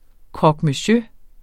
Udtale [ kʁʌgmœˈɕø ]